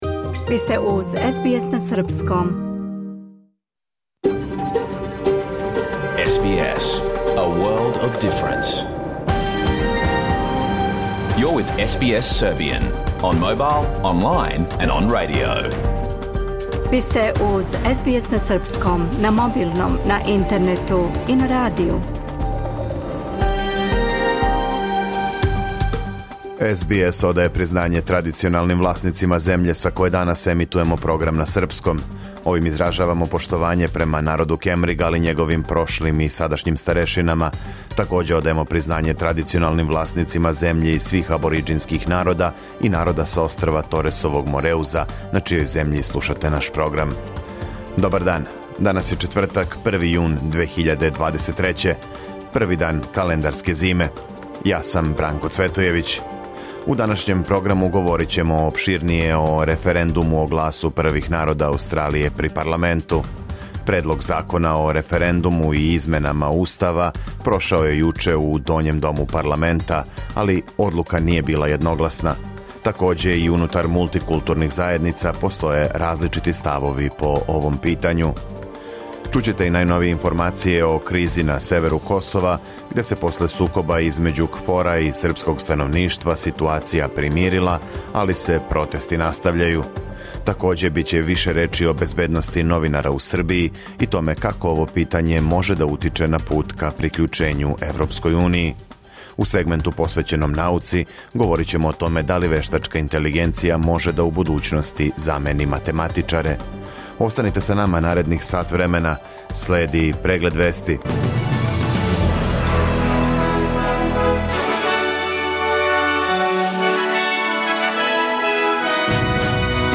Програм емитован уживо 1. јуна 2023. године
Ако сте пропустили данашњу емисију, можете да је слушате у целини као подкаст, без реклама.